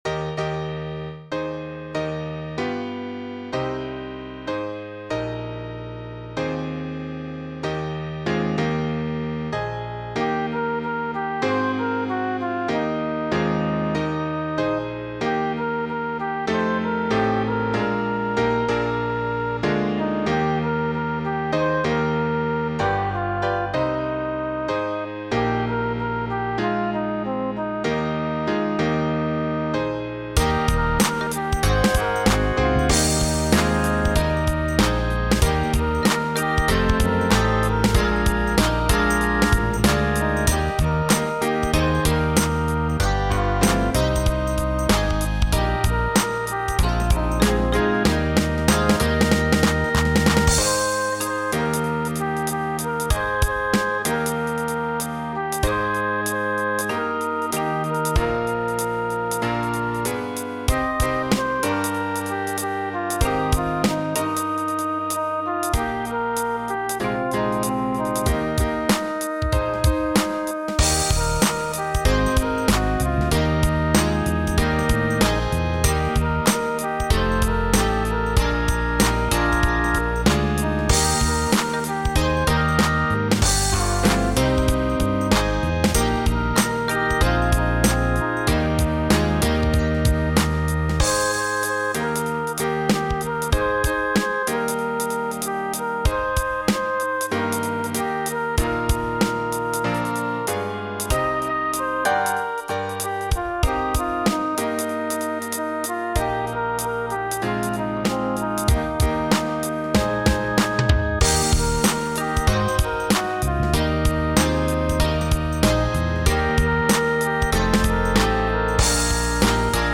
gospel song
I used an old MIDI style on BIAB for this.